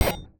UIClick_Menu Mallet Metal Hollow 01.wav